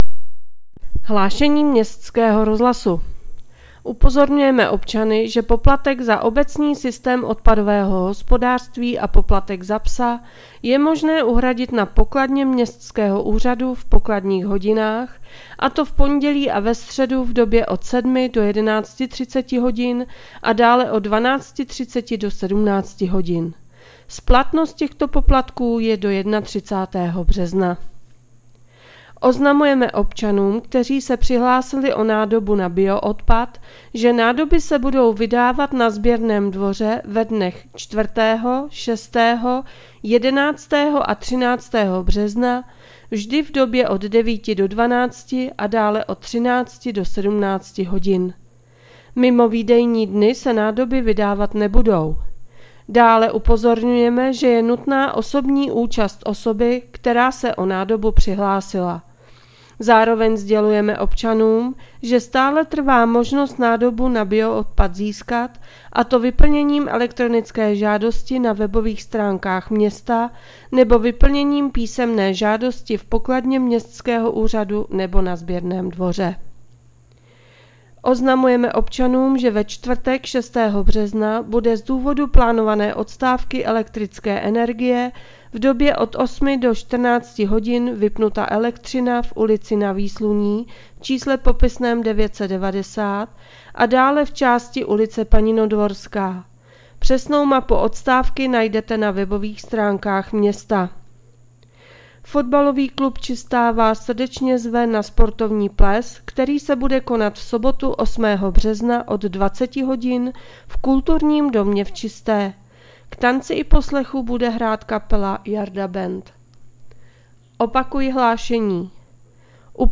Hlášení městského rozhlasu 3.3.2025